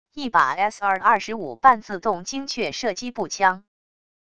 一把sr25半自动精确射击步枪wav音频